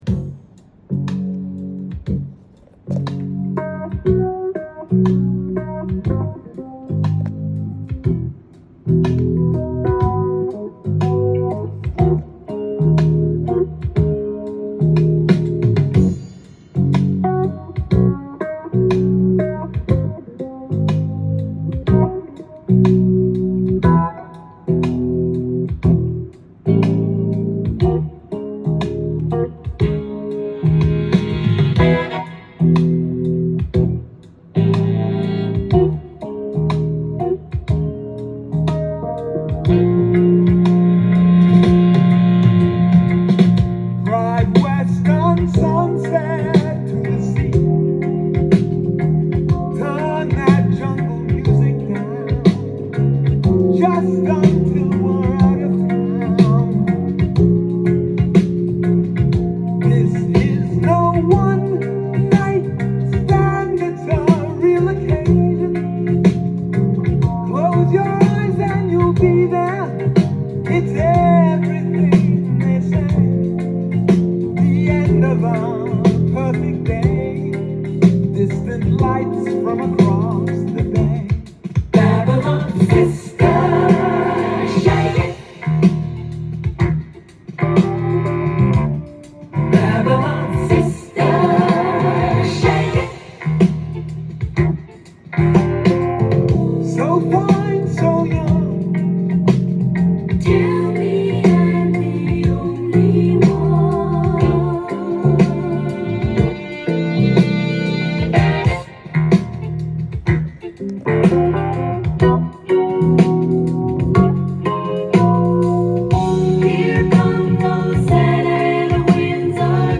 ジャンル：AOR
店頭で録音した音源の為、多少の外部音や音質の悪さはございますが、サンプルとしてご視聴ください。